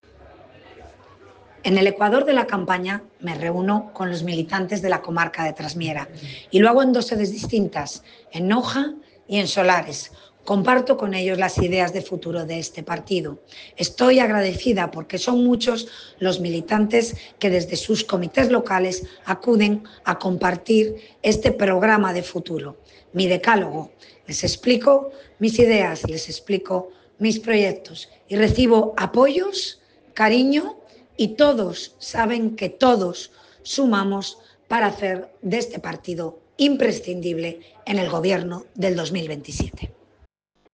Declaraciones de Paula Fernández Viaña tras los encuentros con militantes de Trasmiera Nota de prensa encuentros con militantes de Trasmiera Foto en alta resolución del encuentro en Noja Foto en alta resolución del encuentro en Solares